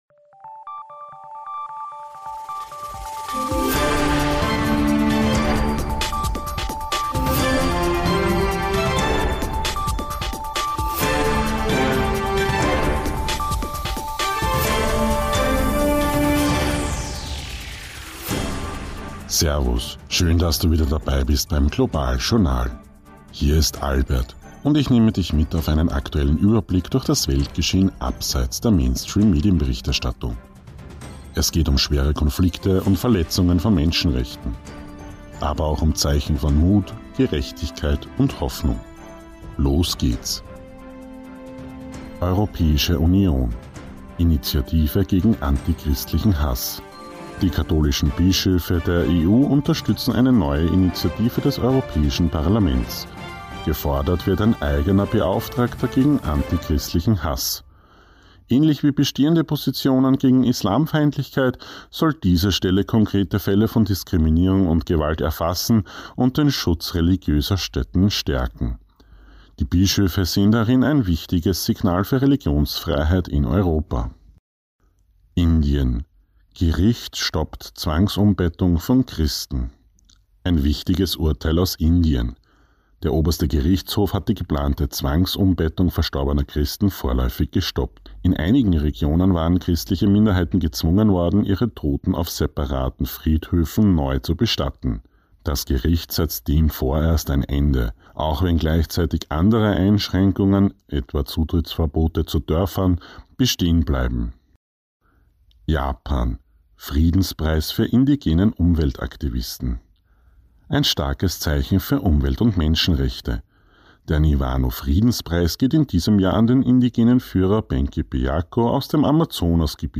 News Update April 2026